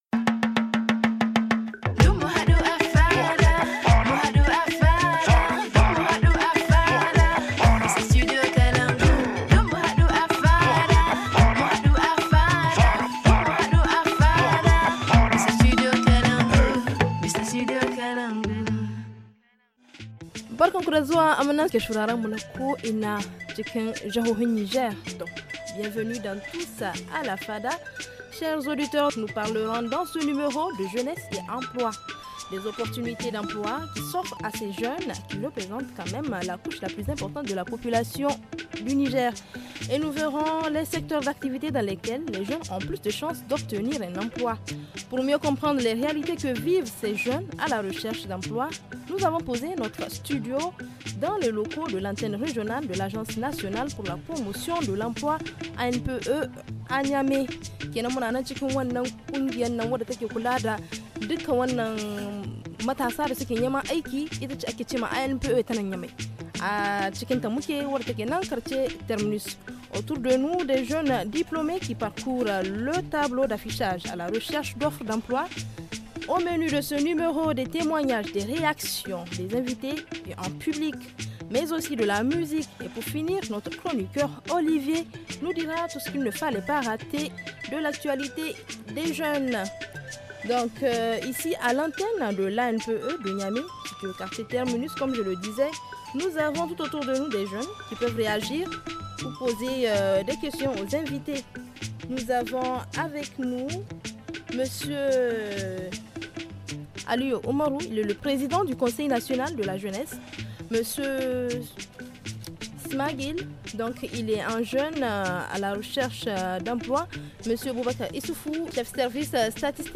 Pour avoir des informations fiables, notre équipe s’est installée à l’antenne régionale de l’Agence Nationale pour la Promotion de l’Emploi-ANPE de Niamey.